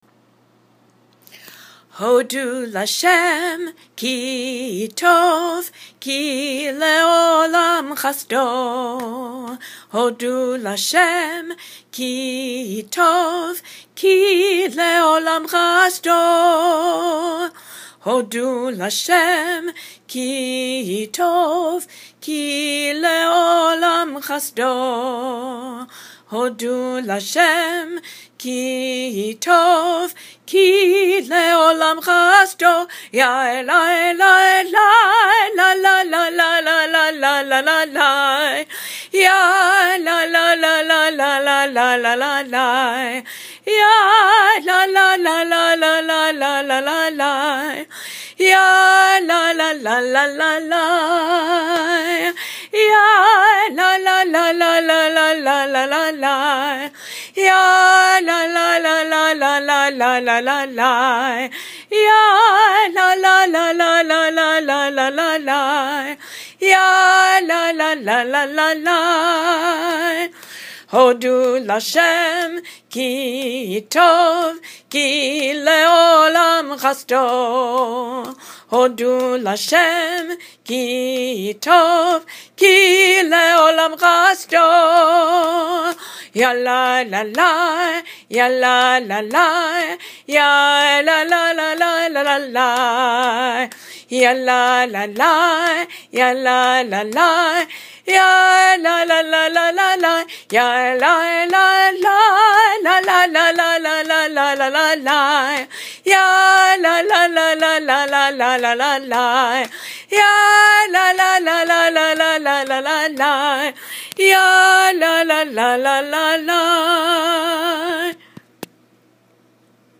And a song for the children: